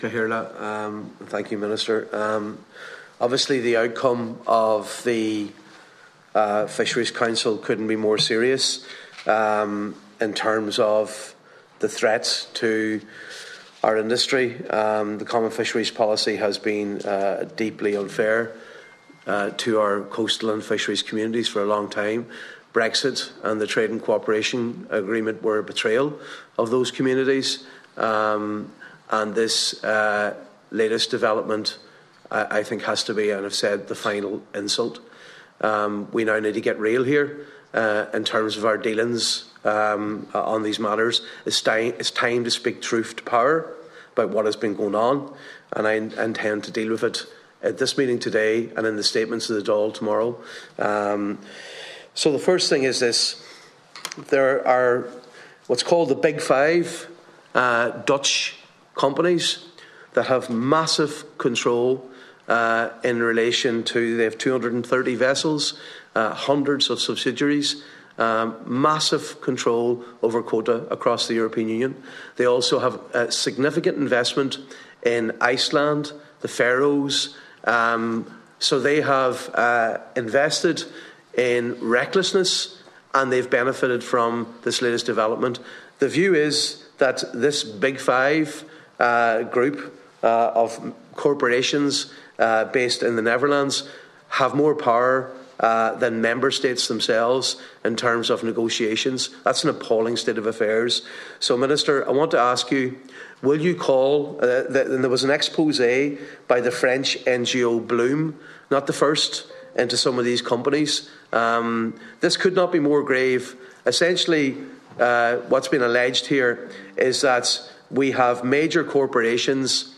Speaking at today’s Oireachtas Committee, Deputy Padraig Mac Lochlainn said the outcome of the EU’s Fisheries Council will be devastating for Ireland’s coastal and fishing communities.